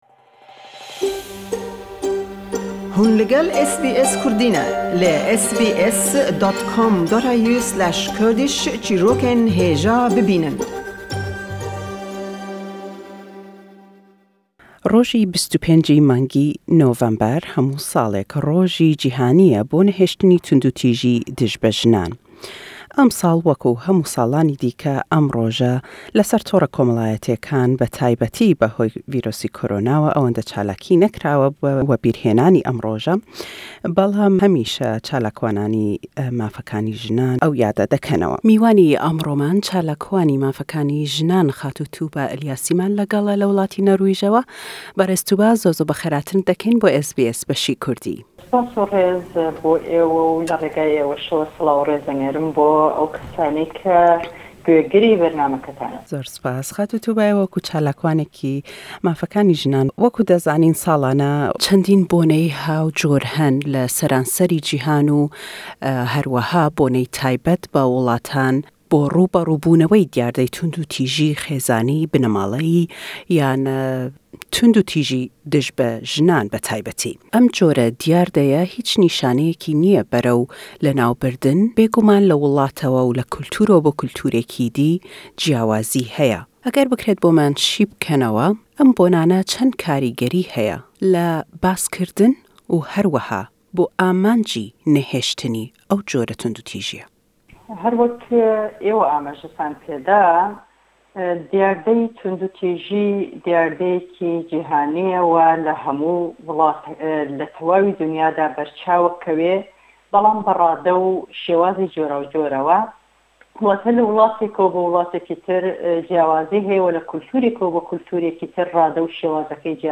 Sallane 25î November Rojî Cîhanî ye bo Nehêştinî Tûndûtîjî Dij be Jinan. Le em lêdwane da le gell çalakwanî mafekanî jinan